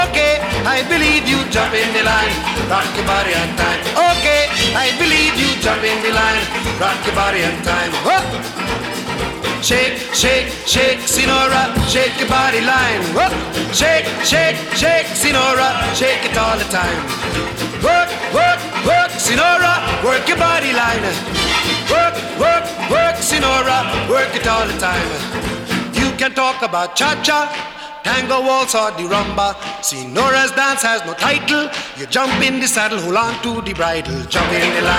# Calypso